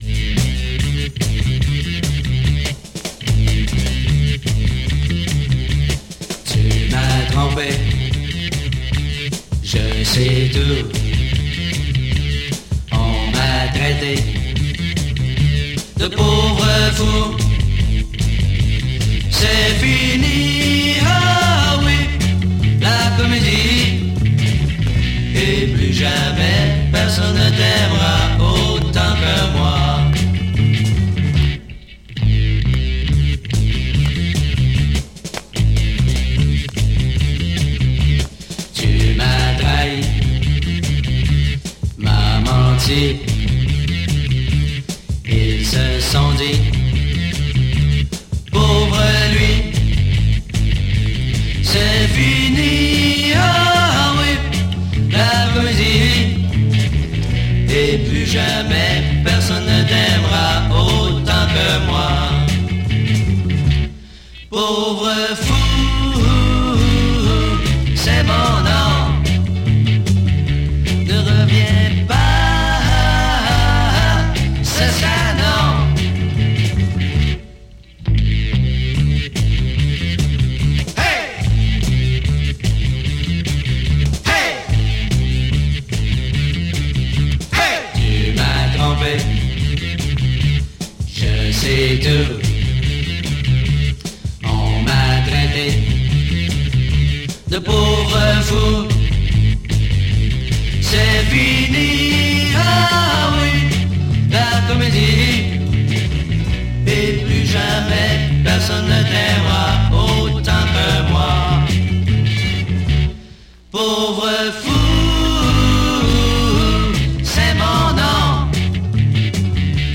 Killer bass Fuzz Quebec Garage
Monster bass fuzz sound for this French Quebec combo!